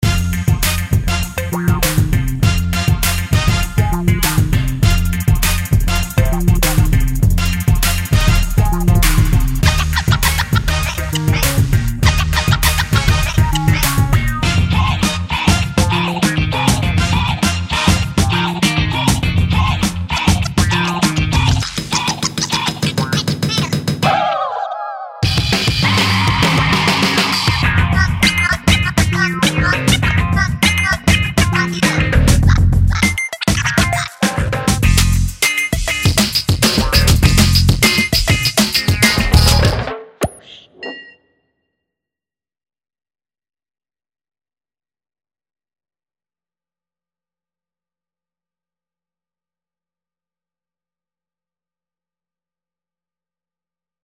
Largo [40-50]
piano - calme - melodieux - melancolique - triste